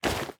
equip_leather3.ogg